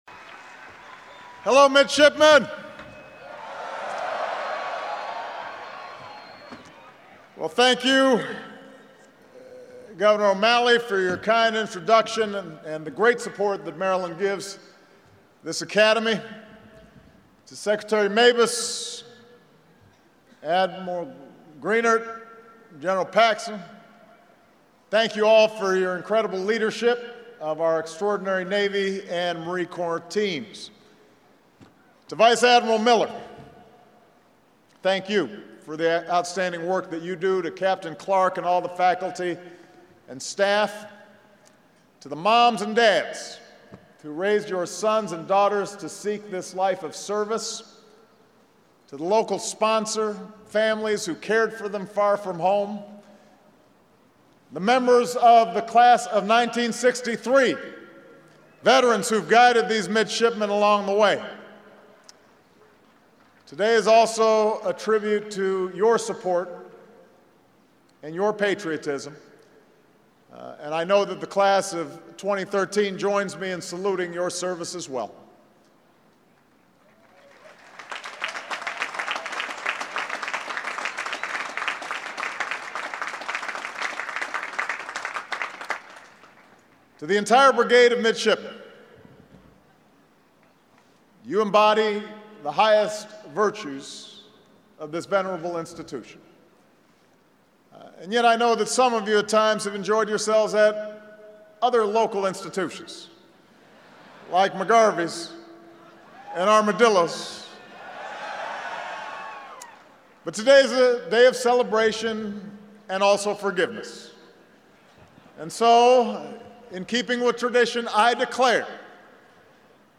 President Obama delivers the 2013 commencement address at the U